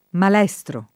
[ mal $S tro ]